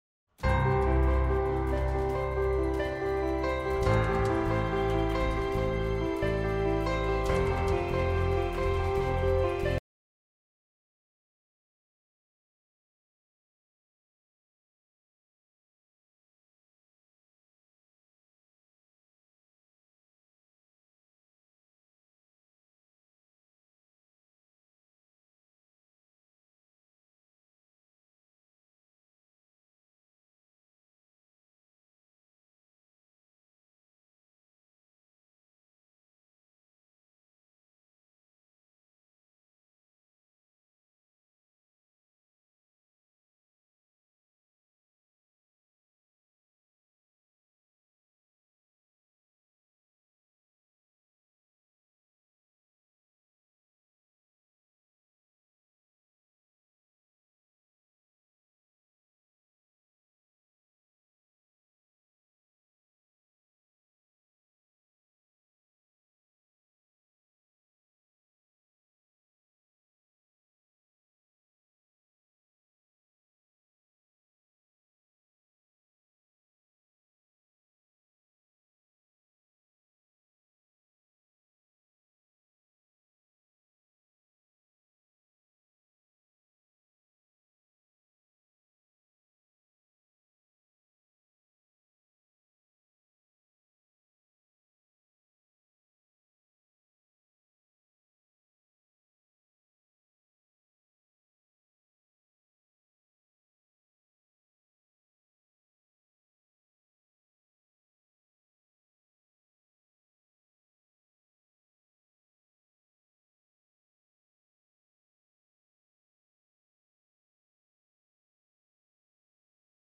Ce sermon explore le sujet de notre responsabilité personnelle envers Dieu après que nos péchés nous ont été pardonnés, et que nous avons reçu la grâce divine. L’apôtre Jacques décrit sept devoirs qui nous incombent en tant que chrétiens, afin de pouvoir passer de l’état d’ennemis de Dieu à celui d’imitateur de Jésus-Christ.